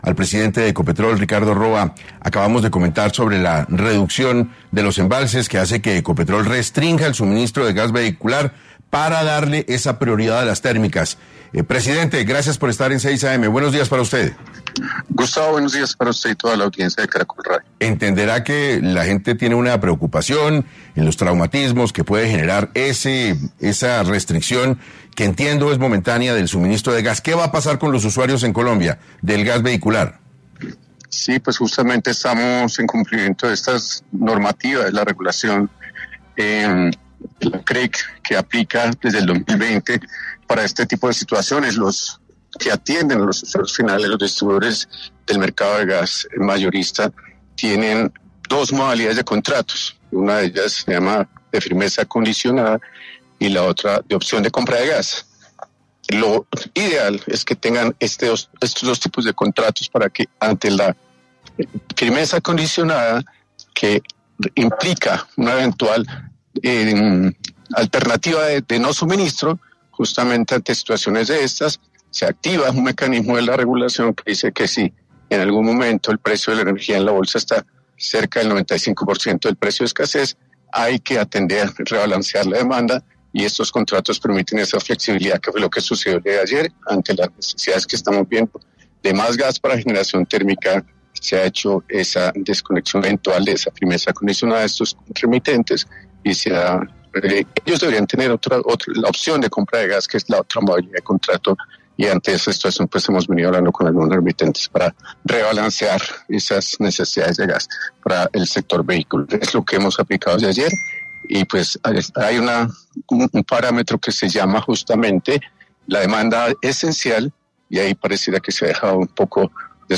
En 6AM de Caracol Radio estuvo Ricardo Roa, presidente de Ecopetrol, para hablar sobre qué pasará con los usuarios de gas vehicular en Colombia tras la decisión de suspender la venta a 13 empresas.